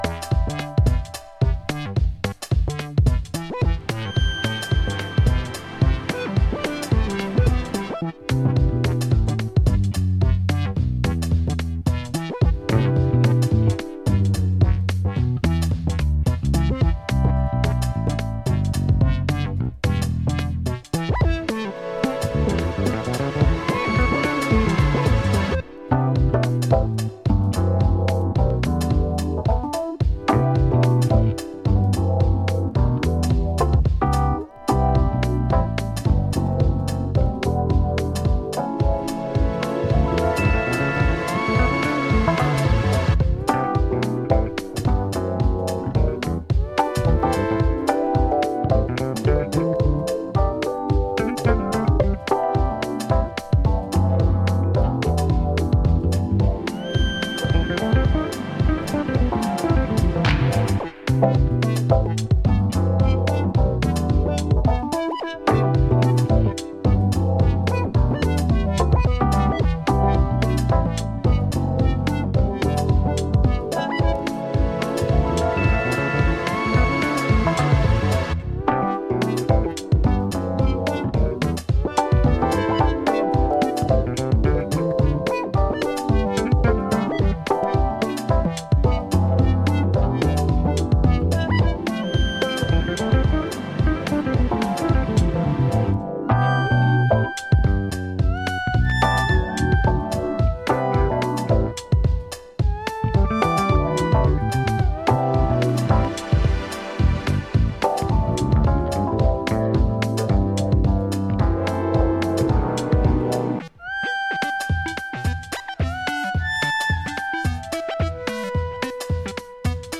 Glaswegian duo